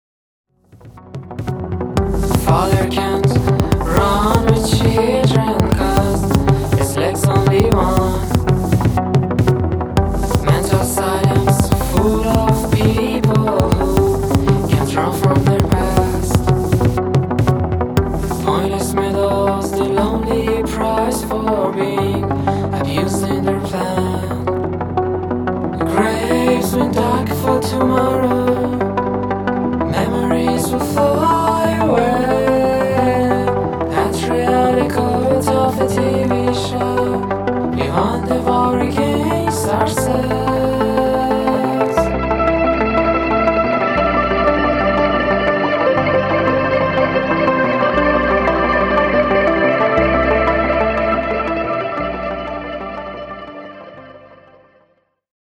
plaintive vocals brought to the foreground
a more ethereal musical landscape